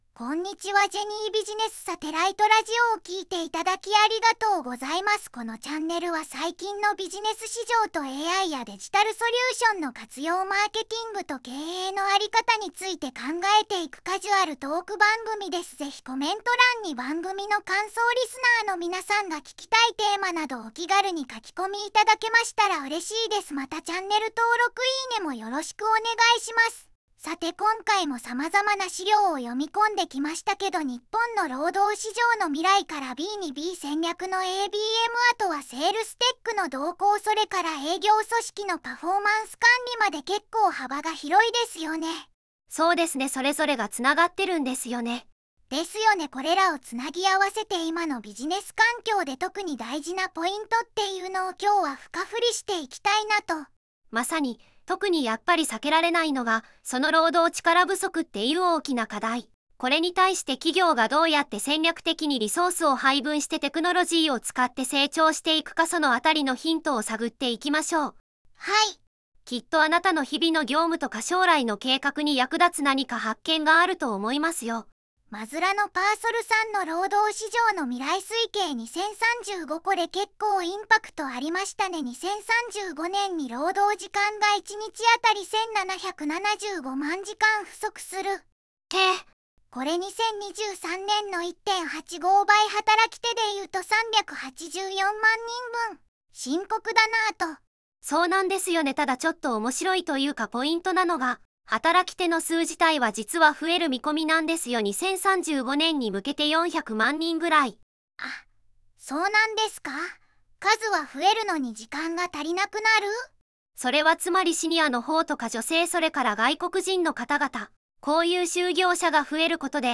VOICEVOXでの合成結果
• SPEAKER_00: 四国めたん (ノーマル, ID: 2)
• SPEAKER_01: ずんだもん (ノーマル, ID: 3)
• VOICEVOXは、同様の語句でアルファベット読みや異なる読み方（例: エスエーエーエス、ろうどうちからぶそく）になる場合があったが、辞書登録機能で修正可能
metan-zundamon.wav